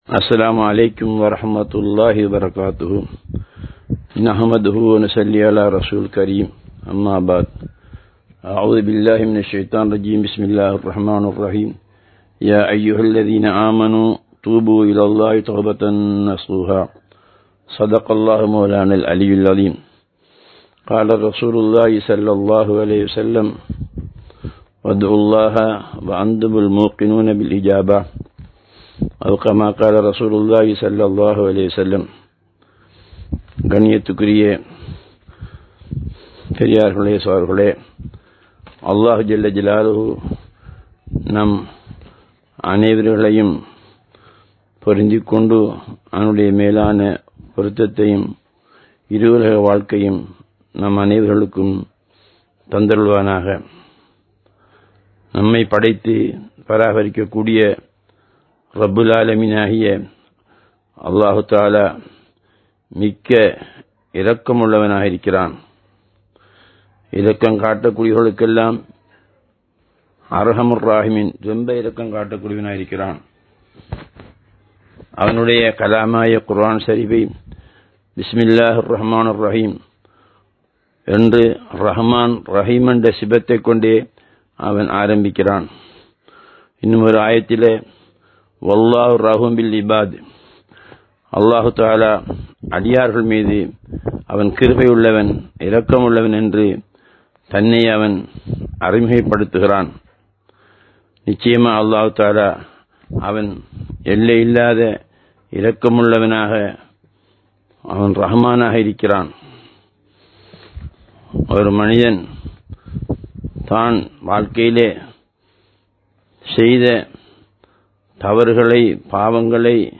Rahmaththum Maufiraththum (றஹ்மத்தும் மஃபிரத்தும்) | Audio Bayans | All Ceylon Muslim Youth Community | Addalaichenai
Live Stream